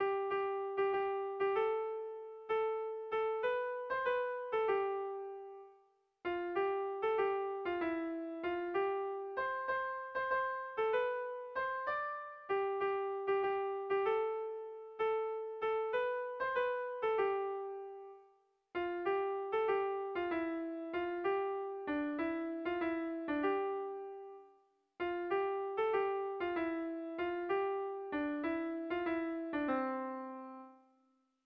Kontakizunezkoa
Zortziko txikia (hg) / Lau puntuko txikia (ip)
AB1AB2B3